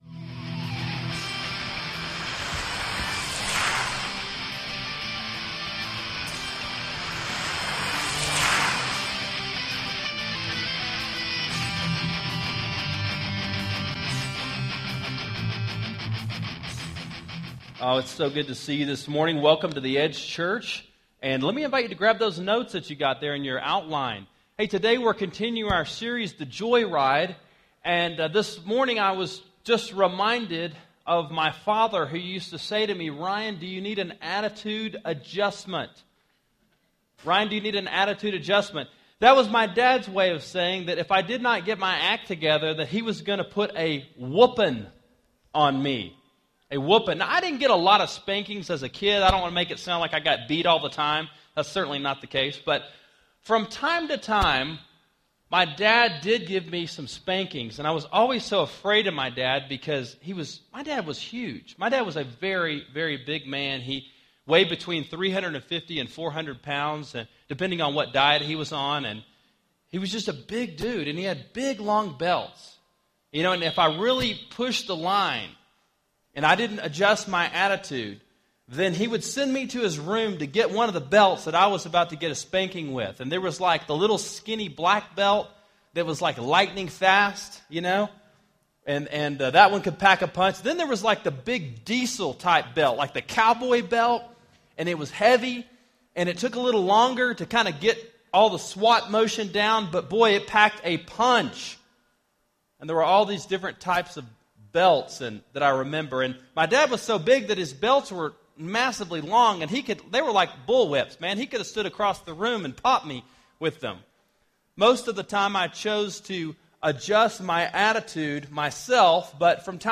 Joy Ride: Developing A Great Attitude About Life, Philippians 4:6-9 – Sermon Sidekick